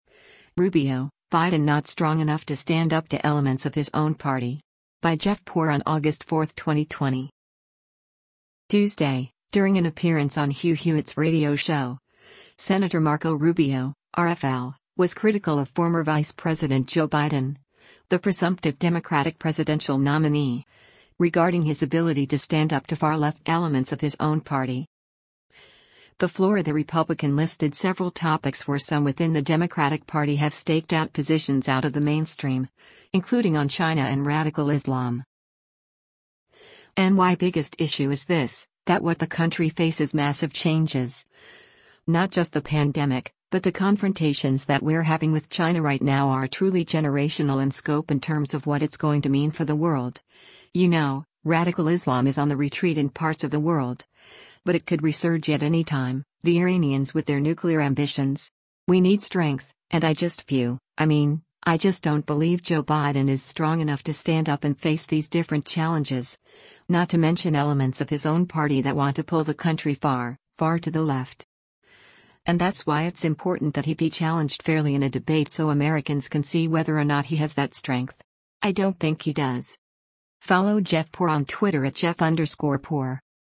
Tuesday, during an appearance on Hugh Hewitt’s radio show, Sen. Marco Rubio (R-FL) was critical of former Vice President Joe Biden, the presumptive Democratic presidential nominee, regarding his ability to stand up to far-left “elements” of his own party.